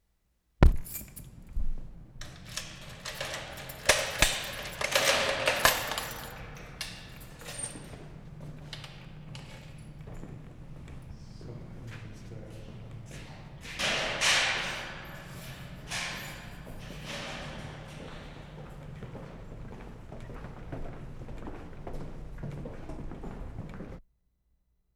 WORLD SOUNDSCAPE PROJECT TAPE LIBRARY
Vienna/ Nussdorf March 19/75
LOCKING GATE IN TUNNEL
2. Good and clear.